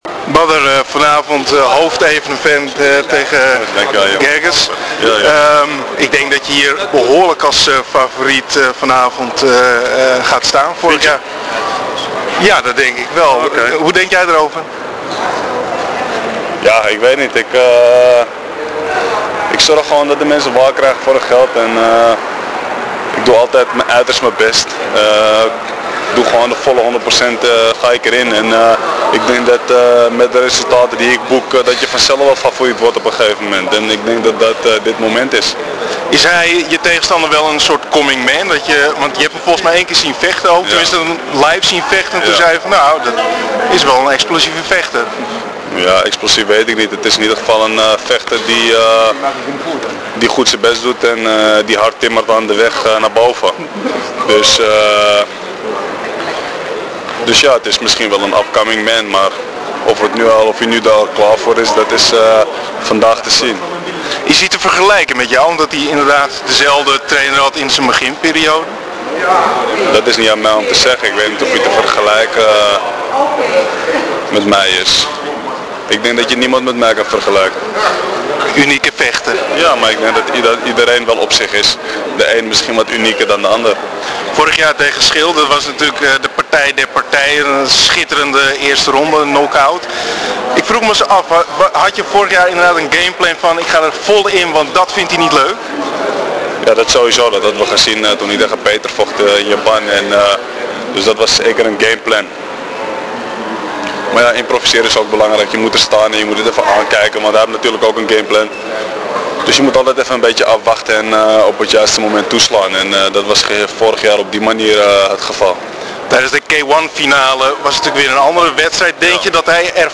Interview Badr Hari vs Hesdy Gerges 2010